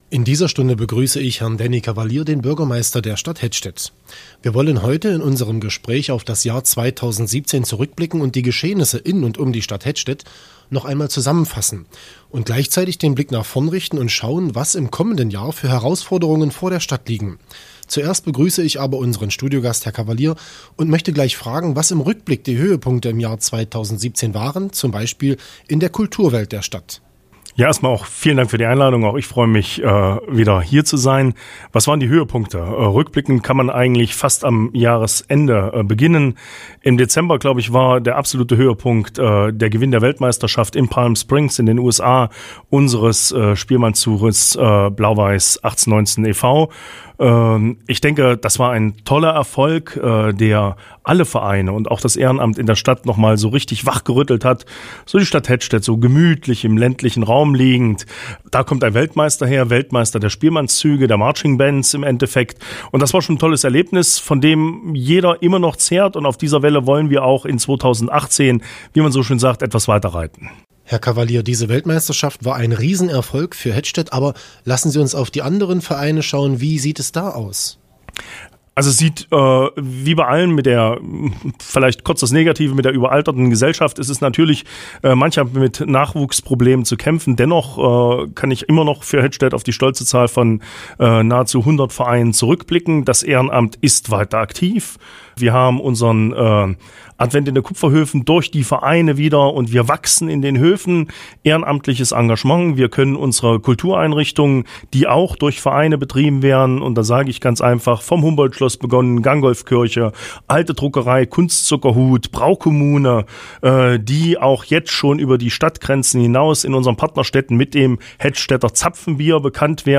Immer zum Jahreswechsel laden wir Lokalpolitiker ein, um Bilanz zum zurückliegenden Jahr zu ziehen und um die Herausforderungen des neuen Jahres zu betrachten. Was das Jahr 2017 gebracht hat und was das Jahr 2018 für Hettstedt bereithält, erklärt Bürgermeister Danny Kavalier.
neujahrsgespraech_hettstedt_2018.mp3